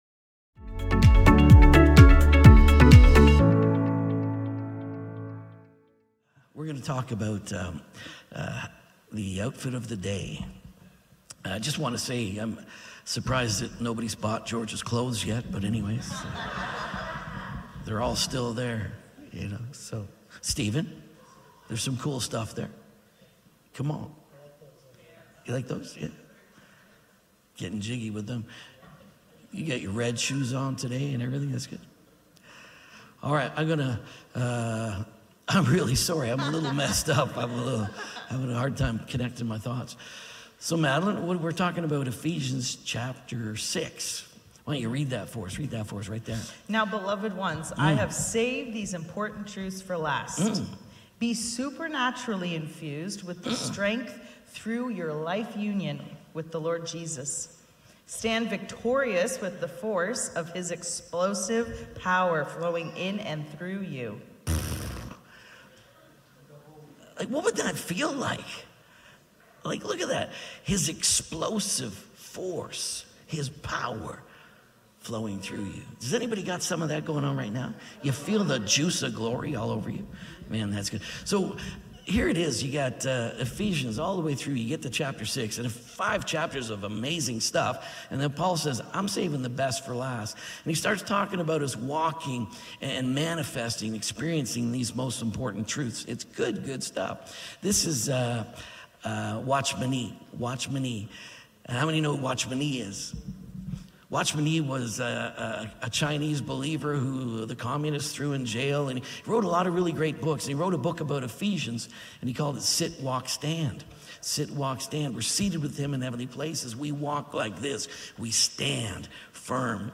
Faces |“Face of Restoration” Sermon